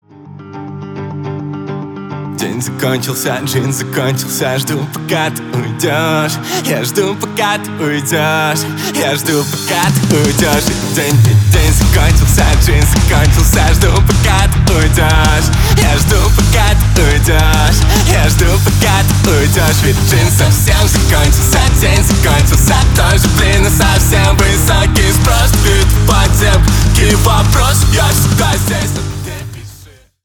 Рок Металл
громкие